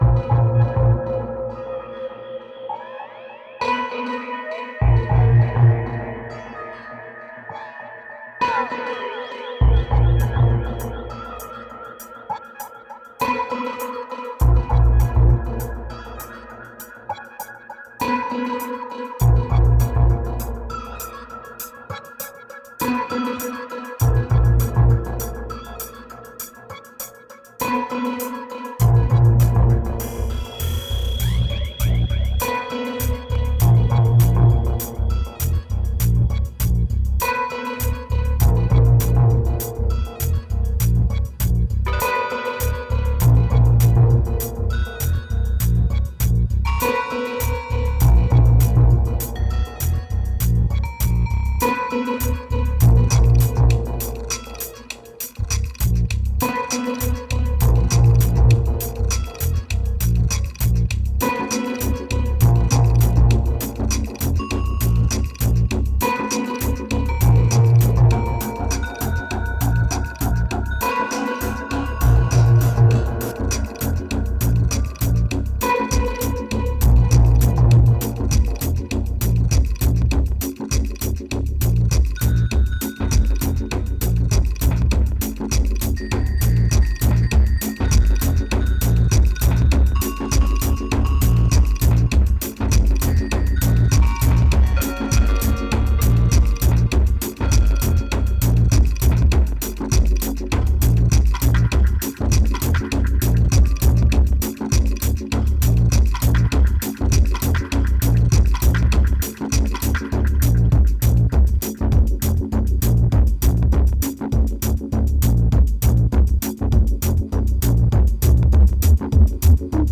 2233📈 - 26%🤔 - 100BPM🔊 - 2011-10-21📅 - -42🌟